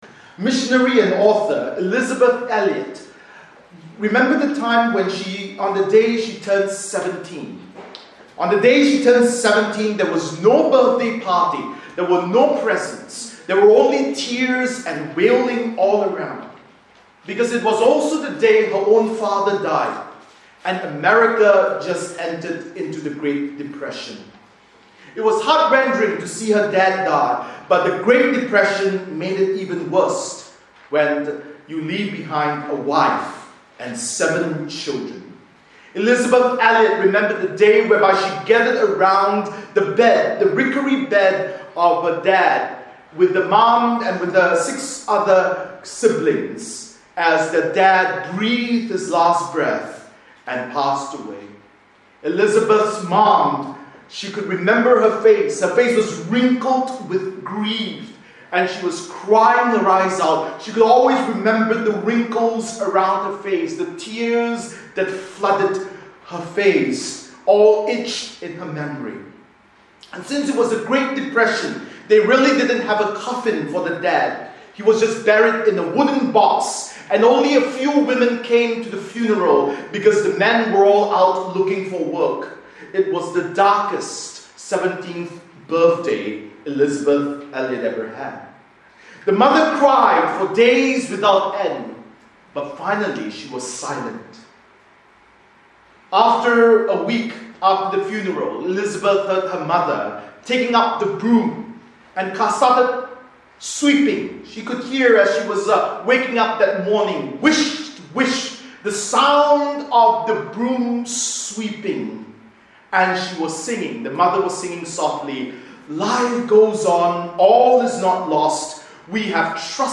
Bible Text: Zephaniah 1:2-6 | Preacher